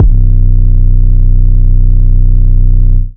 Real Uptown 808.wav